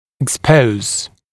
[ɪk’spəuz] [ek-][ик’споуз] [эк-]делать видимым, обнажать; выявлять